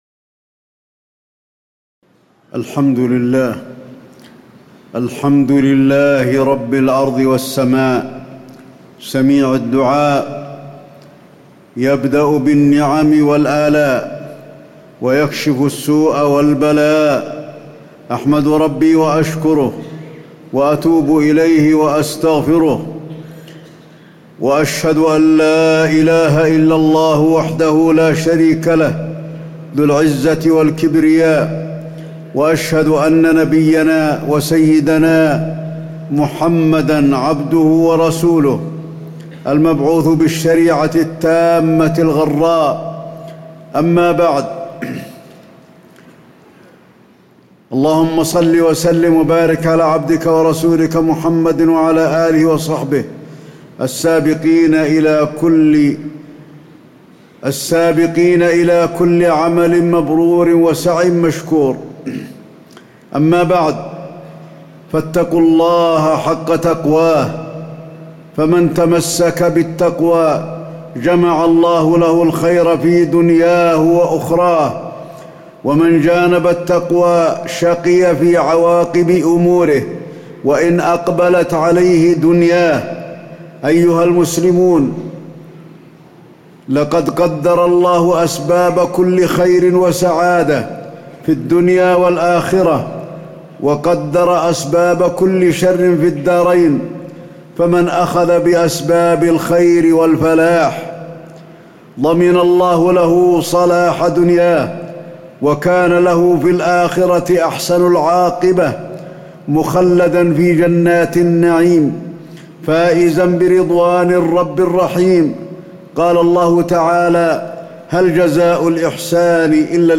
تاريخ النشر ٢٩ ربيع الثاني ١٤٣٨ هـ المكان: المسجد النبوي الشيخ: فضيلة الشيخ د. علي بن عبدالرحمن الحذيفي فضيلة الشيخ د. علي بن عبدالرحمن الحذيفي أحكام الدعاء وآثاره على الفرد والمجتمع